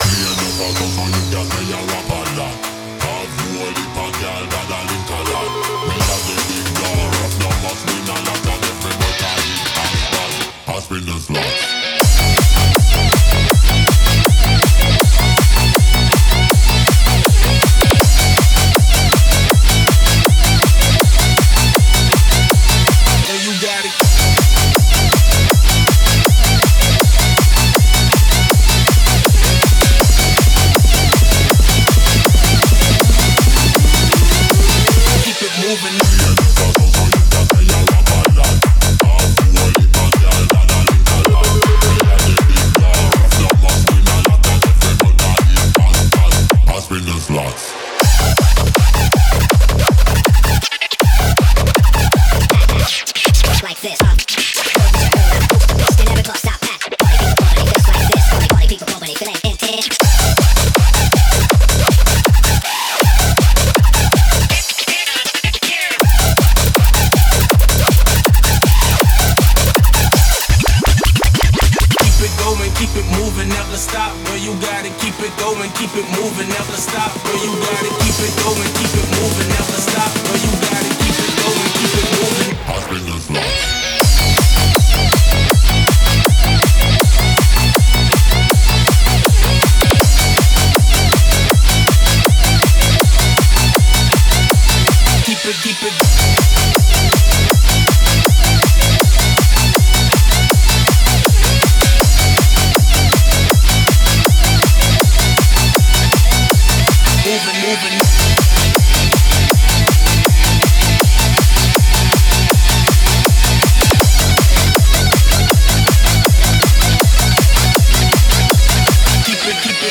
BPM80-320
Audio QualityMusic Cut